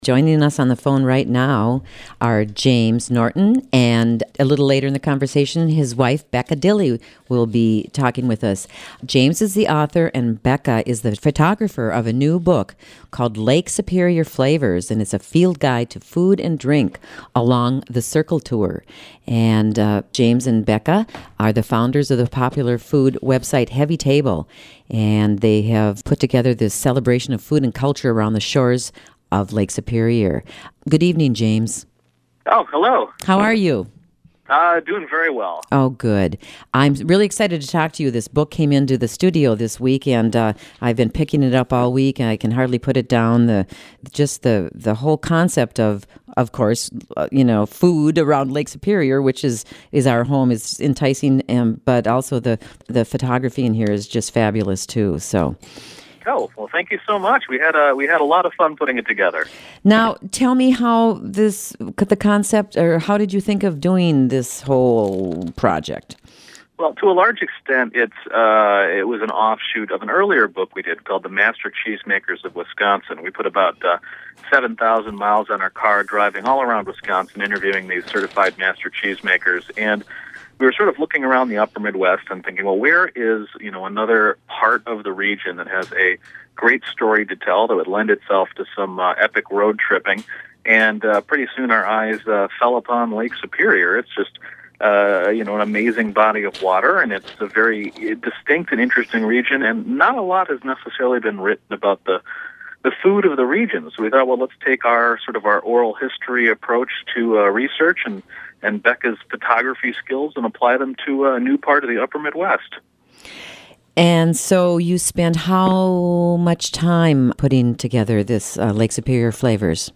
They share their experiences meeting with monks, fishermen, cheesemakers, and many more interesting characters they met along the way. Program: The Roadhouse